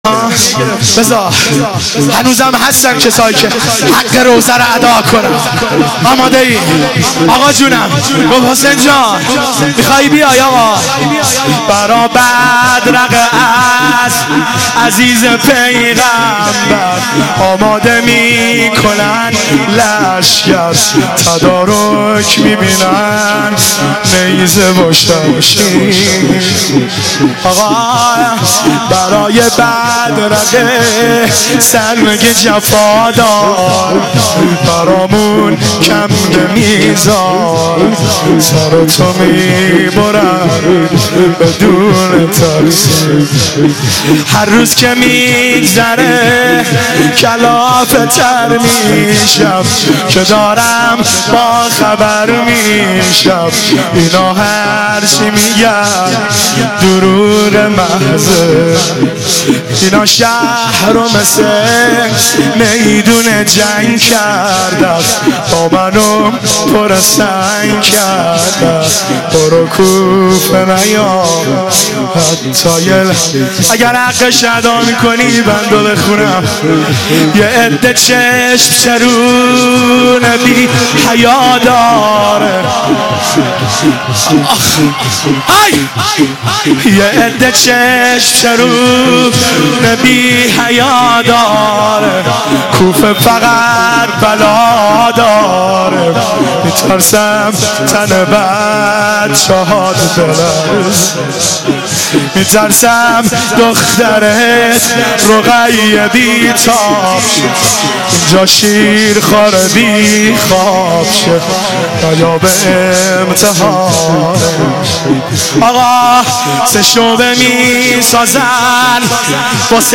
مداحی فوق العاده جانسوز
ایام مسلمیه شب دوم